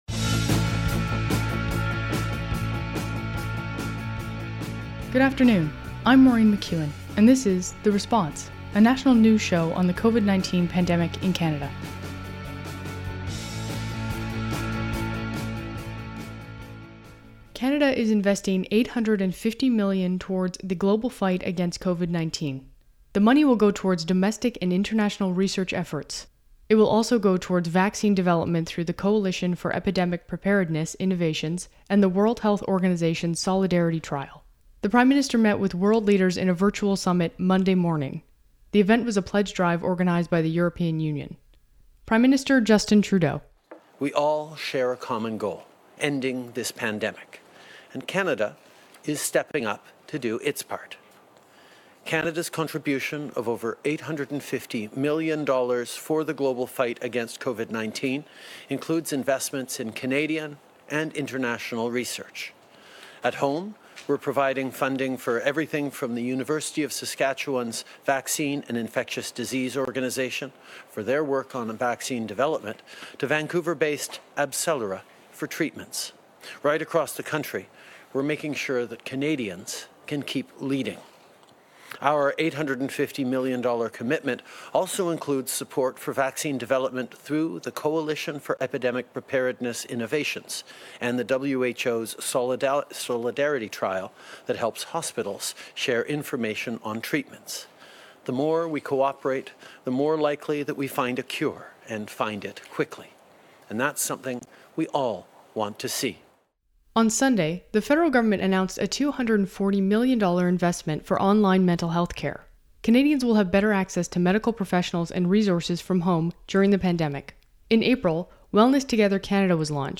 National News Show on COVID-19
Type: News Reports
192kbps Stereo